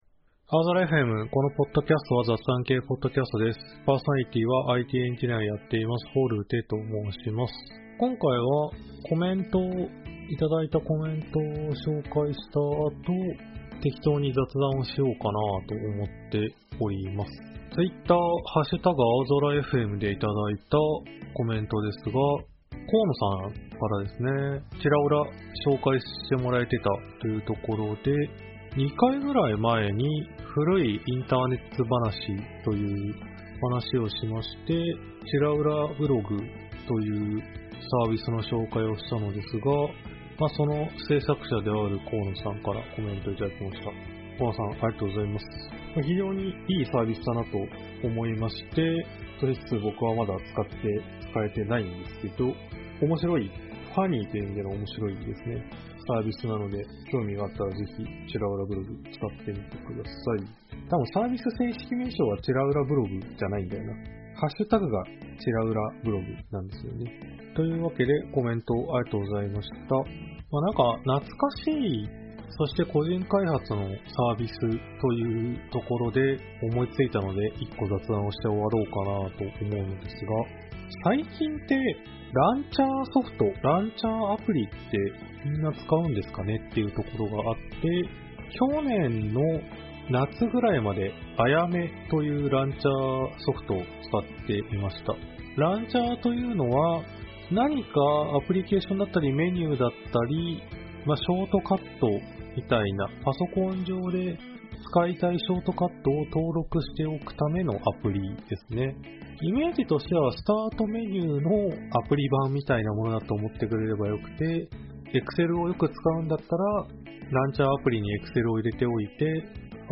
aozora.fmは仕事や趣味の楽しさを共有する雑談系Podcastです。